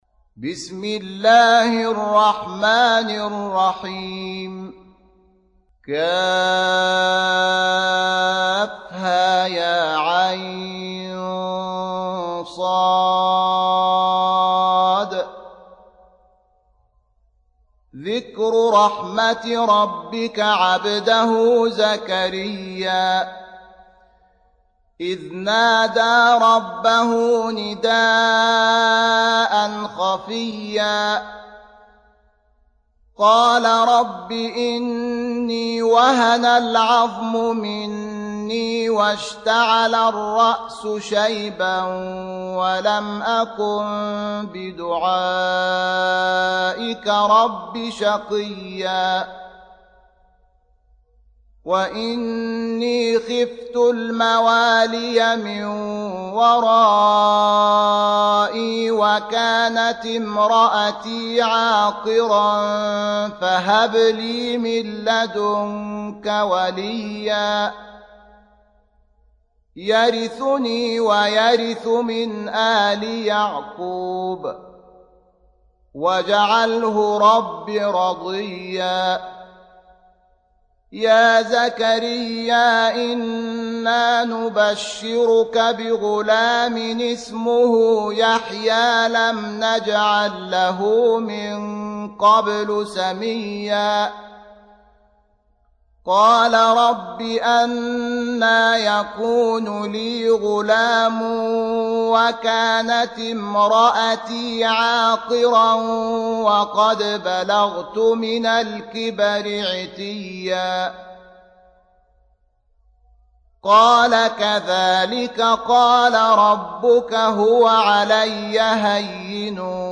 19. Surah Maryam سورة مريم Audio Quran Tarteel Recitation
حفص عن عاصم Hafs for Assem